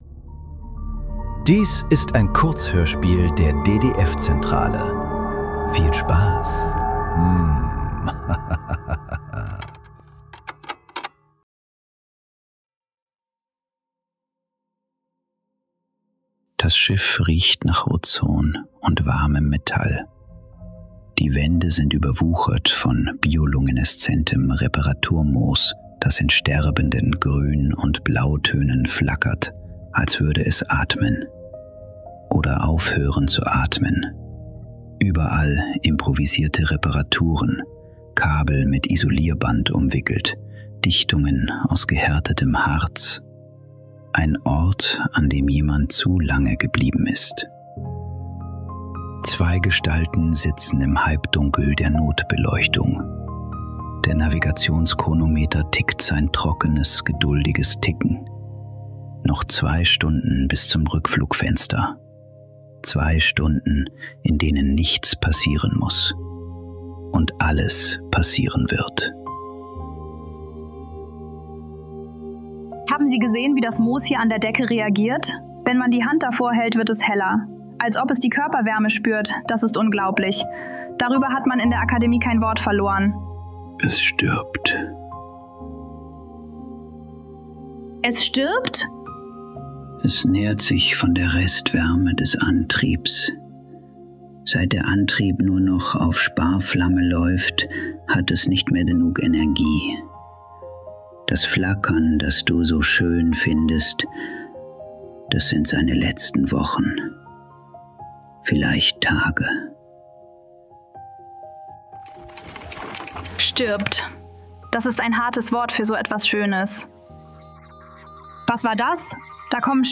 Die Brotdose ~ Nachklang. Kurzhörspiele.